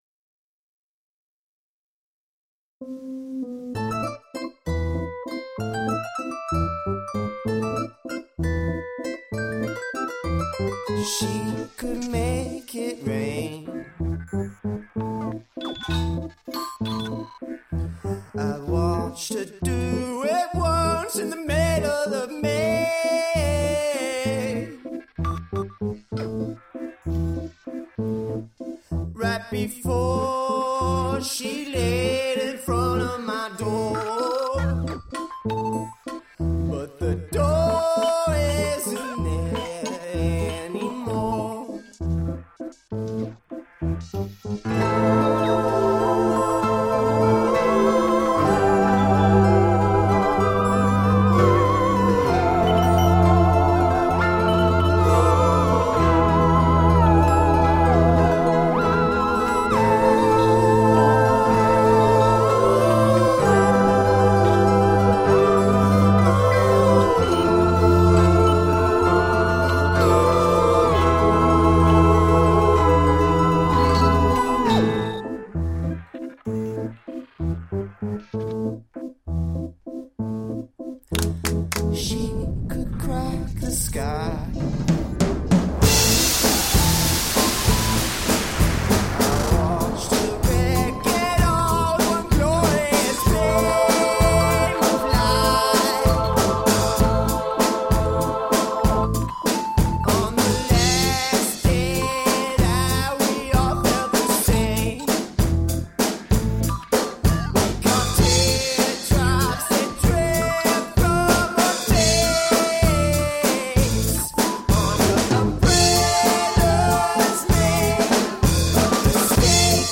Sounds like synth pop walking down a dark alley.
Tagged as: Alt Rock, Folk-Rock, Prog Rock